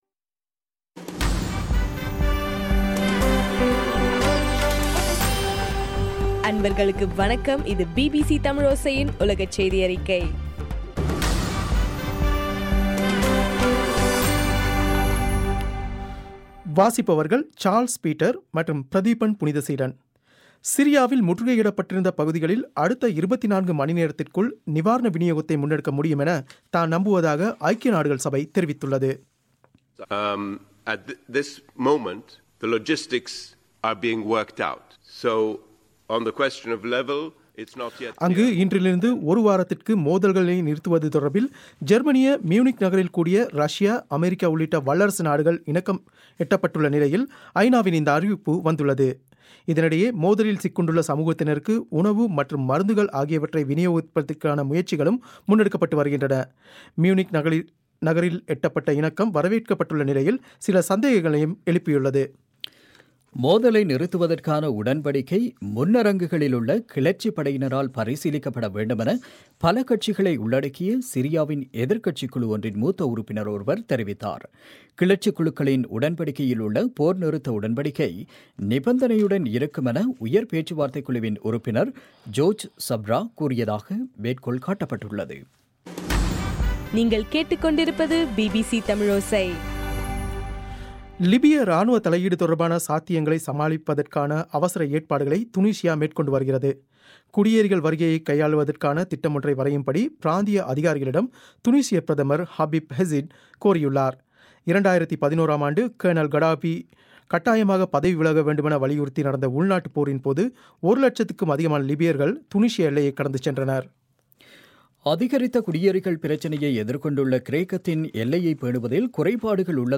பிப்ரவரி 12 பிபிசியின் உலகச் செய்திகள்